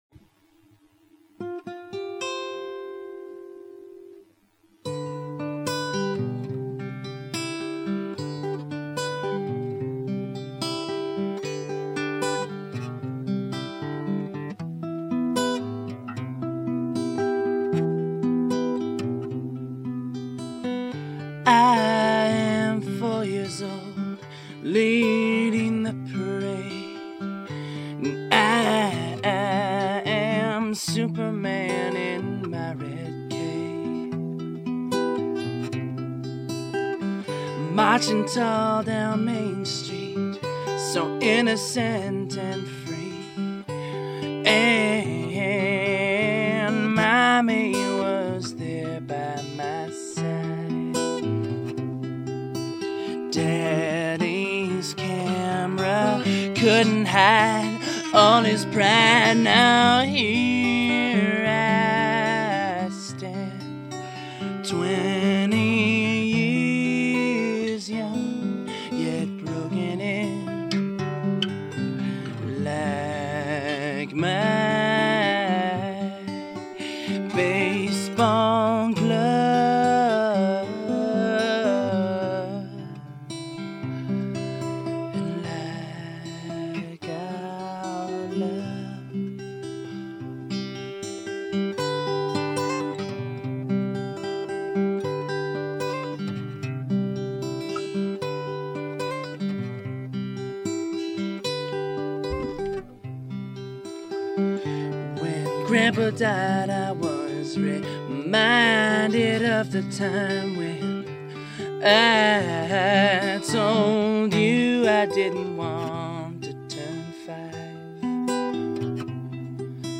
electric guitar, bass guitar, drum kit, & keys